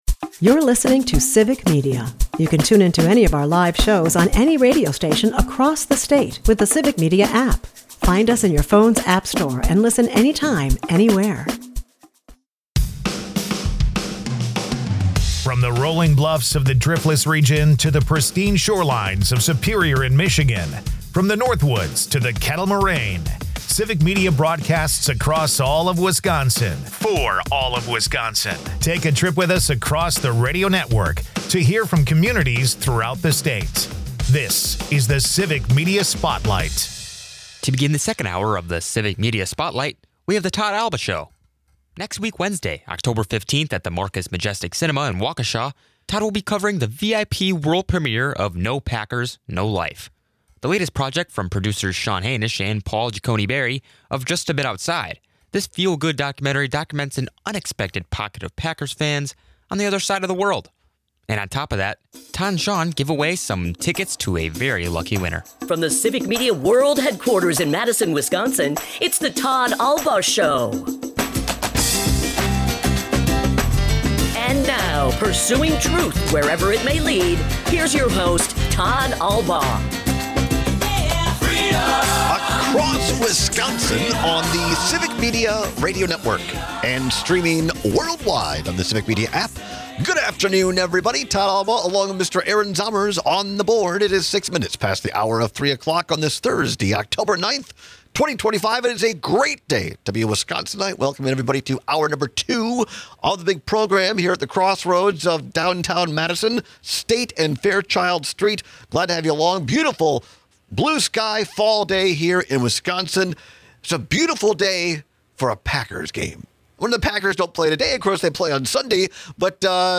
The two songs here were played live for the first time in the studio!